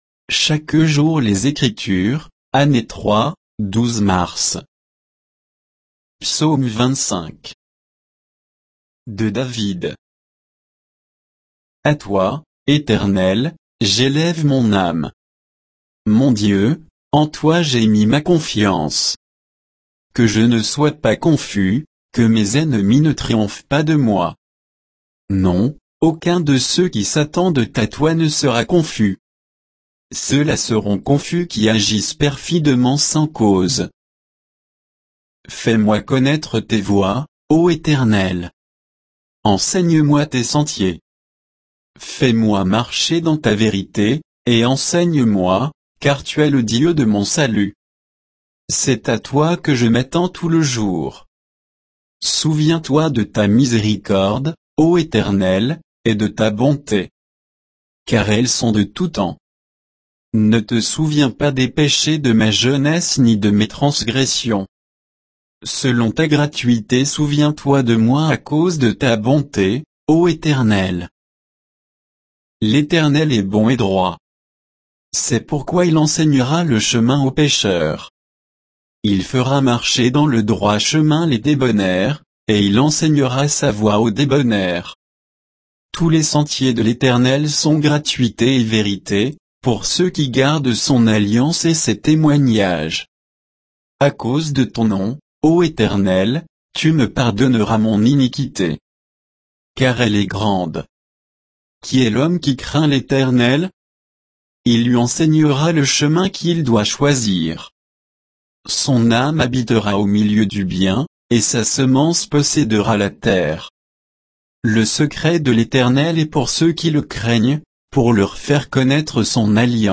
Méditation quoditienne de Chaque jour les Écritures sur Psaume 25